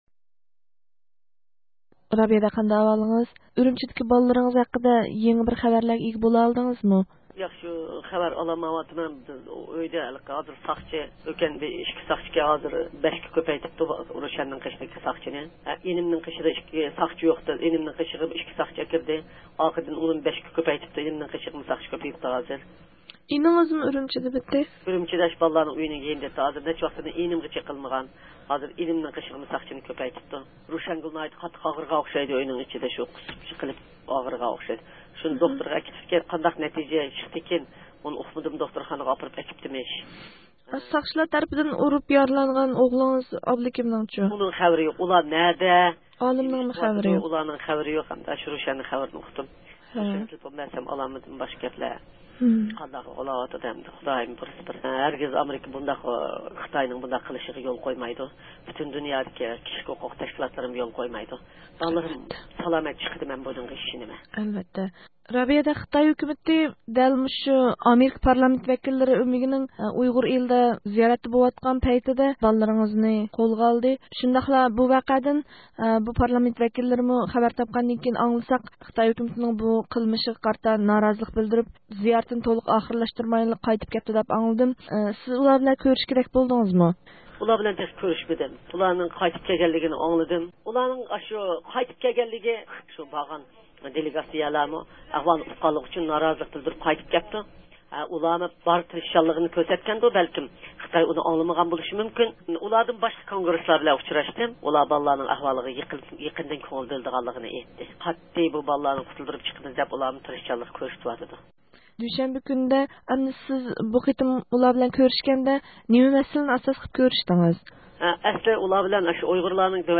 بىز رابىيە قادىر خانىمنىڭ ئۆزىدىن ئۈرۈمچىدىكى پەرزەنتلىرىنىڭ نۆۋەتتىكى ئەھۋاللىرى ھەققىدە يېڭى ئۇچۇرغا ئىگە بولۇش ئۈچۈن، ئۇنىڭ ۋاشىنگتوندىكى ئۇيغۇر كىشىلىك ھوقۇق فوندى ئىشخانىسىغا قايتا تېلېفون قىلىپ زىيارەت قىلدۇق.